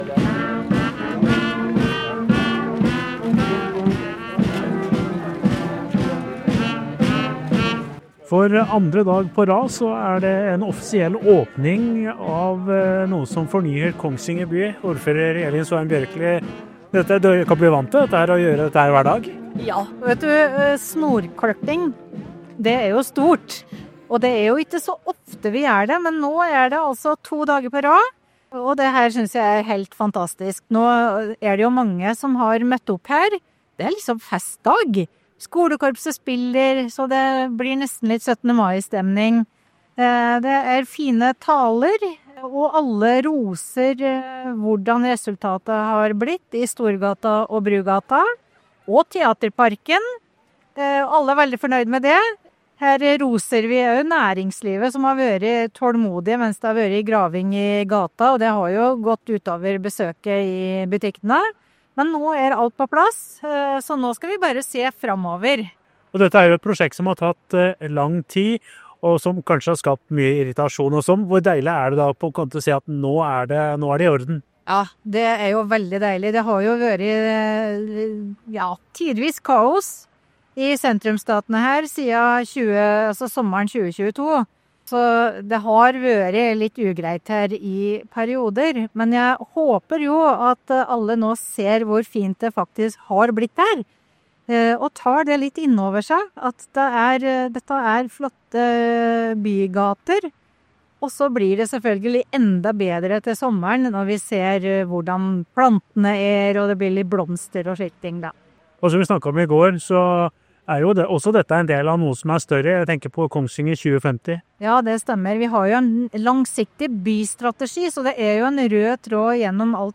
Med skolekorpset spillende og stort oppmøte ble dagen nesten preget av 17. mai-stemning.